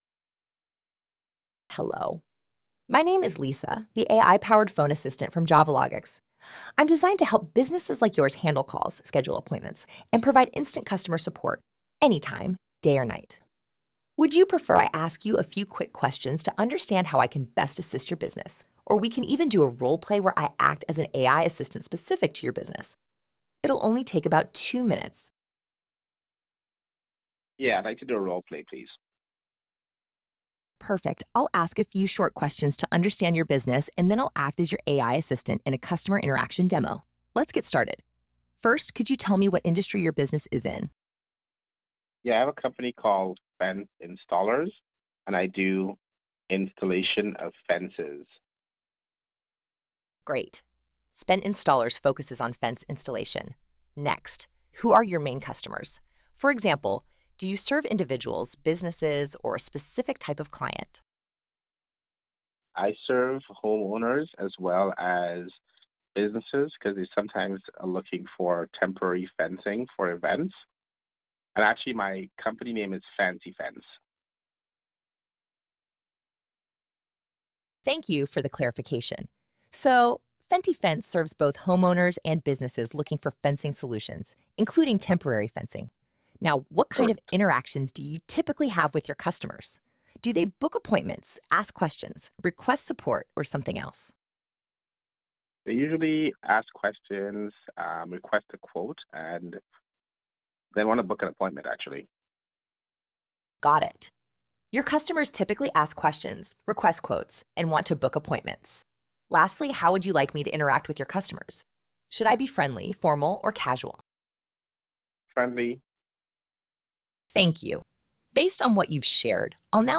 Listen Our RolePlay Agent In Action
A.I.-Voice-Dome.wav